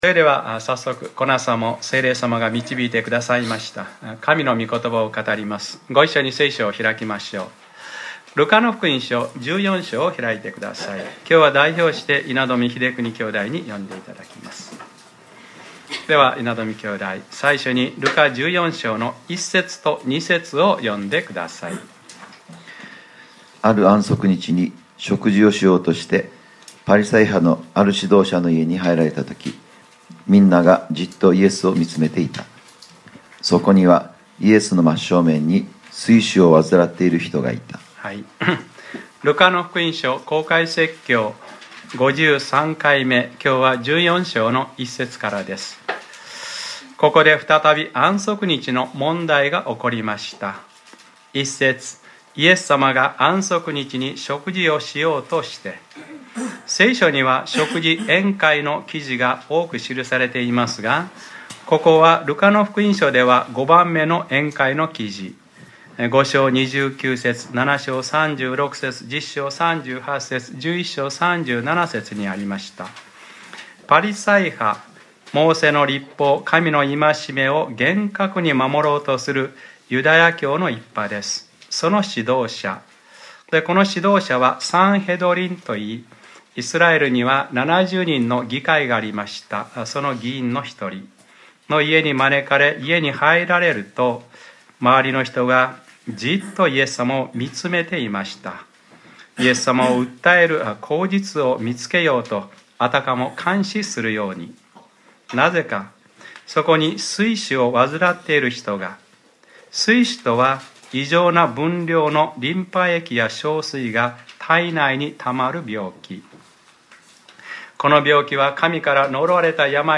2015年02月01日）礼拝説教 『ルカｰ５３：信じた私たちは安息に入るのです』 | クライストチャーチ久留米教会